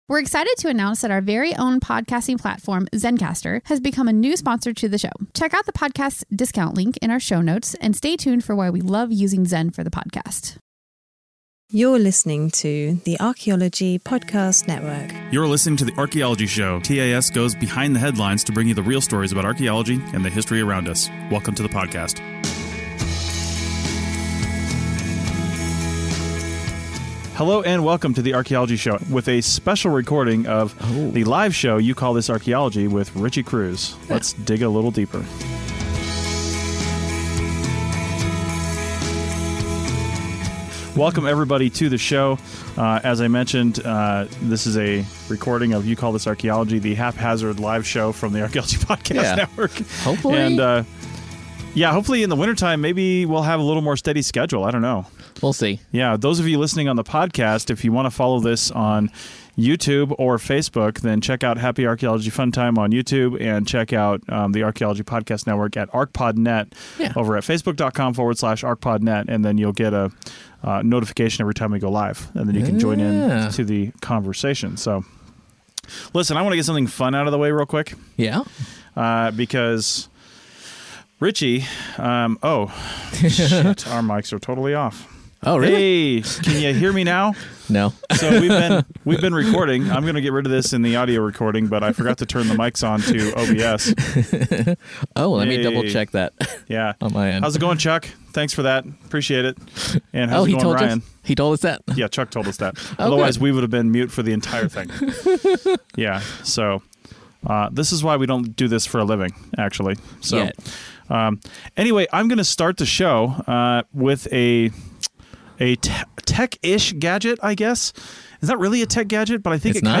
It’s a fun conversation that goes from the archaeological record that we’re starting to produce less and less to drones to whatever else.